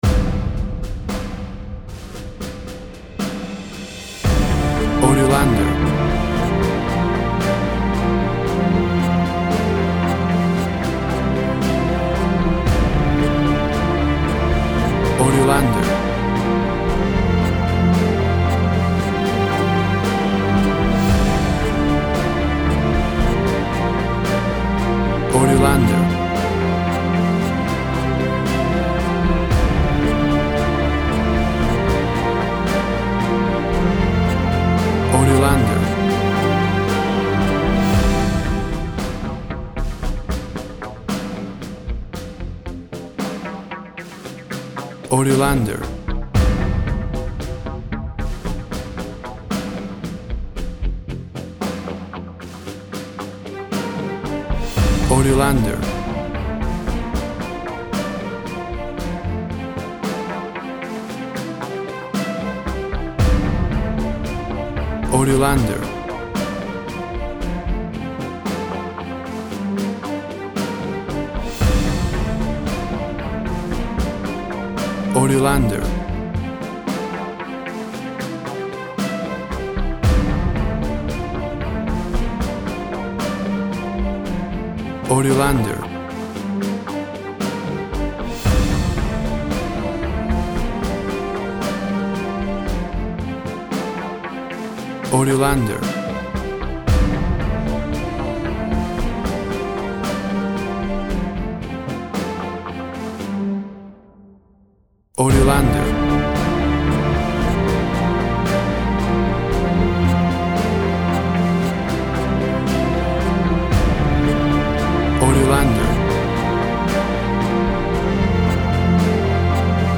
An epic cinematic computer game style track
WAV Sample Rate 16-Bit Stereo, 44.1 kHz
Tempo (BPM) 58